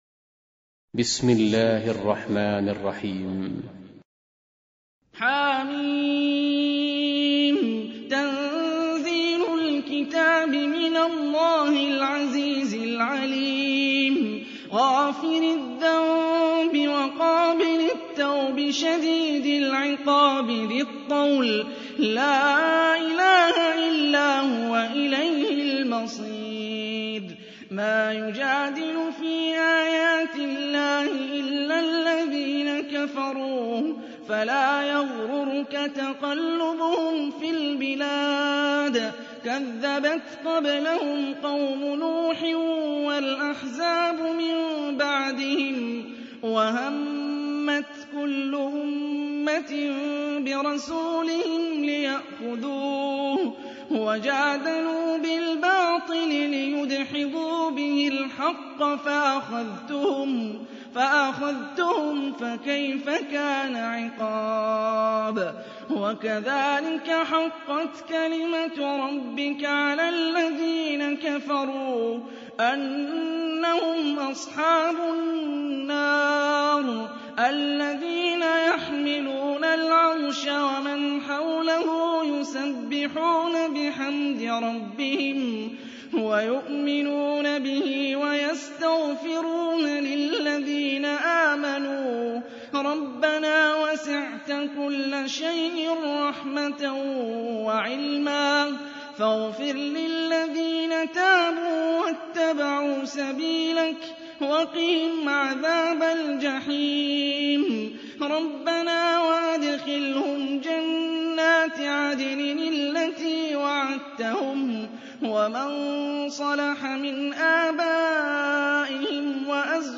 40. Surah Gh�fir سورة غافر Audio Quran Tarteel Recitation
Surah Repeating تكرار السورة Download Surah حمّل السورة Reciting Murattalah Audio for 40. Surah Gh�fir سورة غافر N.B *Surah Includes Al-Basmalah Reciters Sequents تتابع التلاوات Reciters Repeats تكرار التلاوات